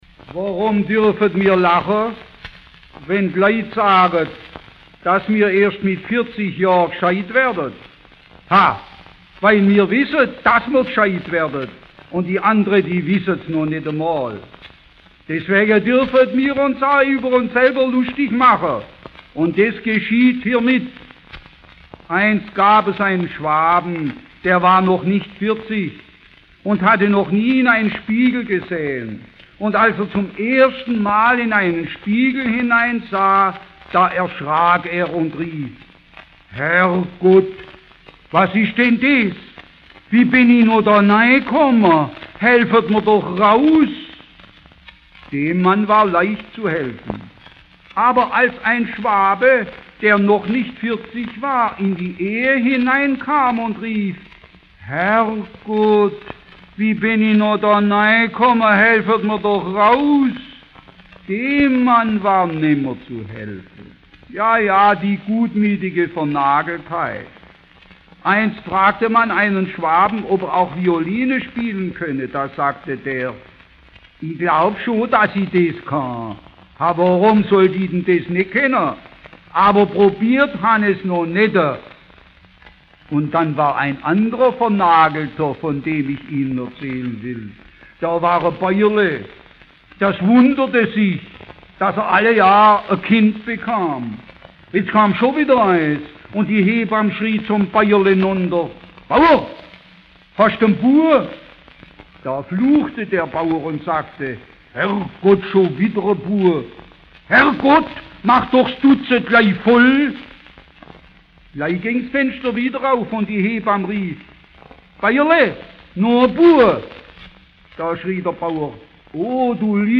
p> Volkskundearchiv Schwoabastreich (Plauderei) Autor(en
Quelle: Schellackplattensammlung Schw�bisches Kulturarchiv